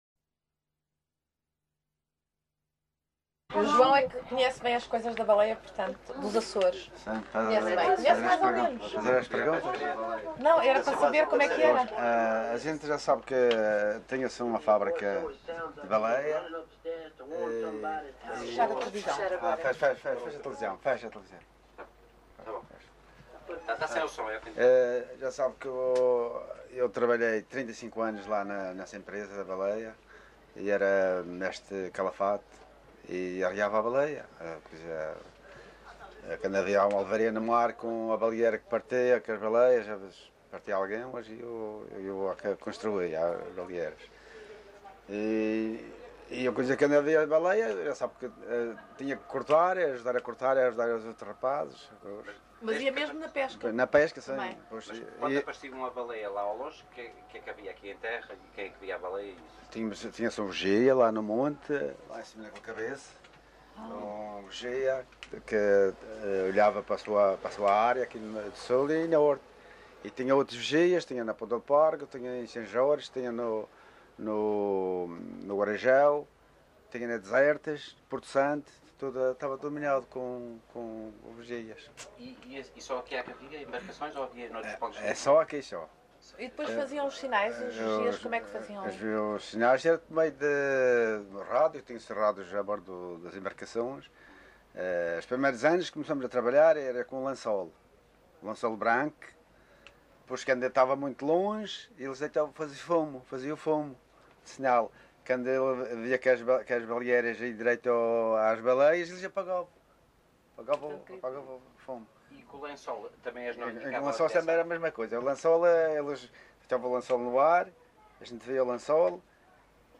LocalidadeCaniçal (Machico, Funchal)